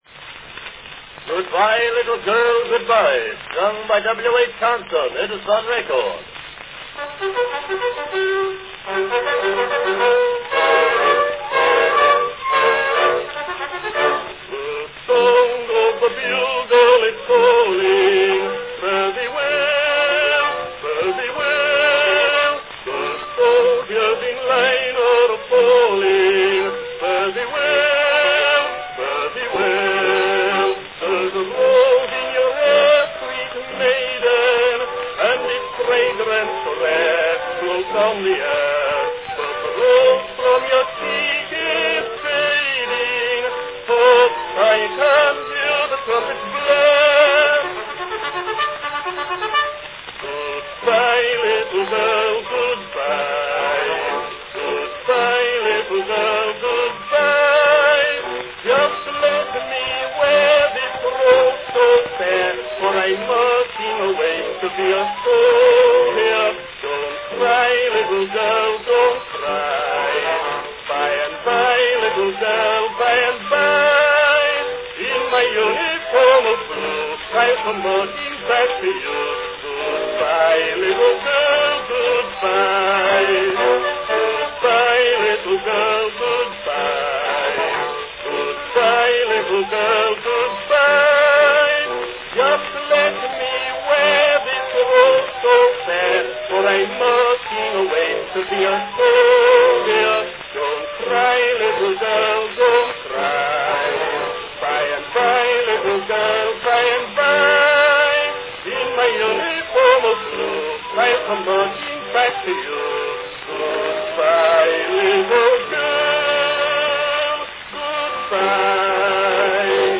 Listen to a complete two-minute wax cylinder recording -- A new cylinder every month.